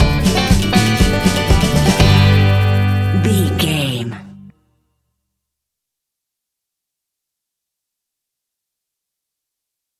Uplifting
Ionian/Major
acoustic guitar
mandolin
drums
double bass
accordion